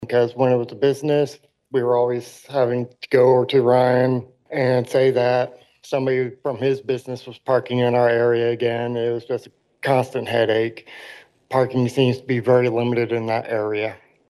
A neighbor expressed a parking concern during public comment.